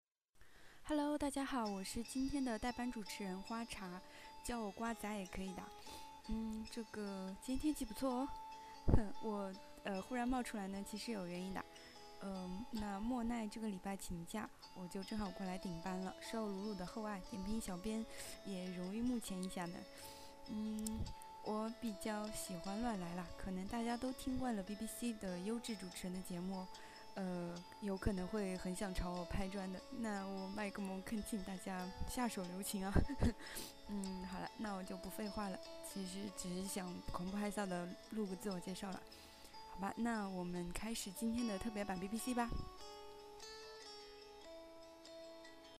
【英音模仿秀】印尼欲加入“金砖国家” 听力文件下载—在线英语听力室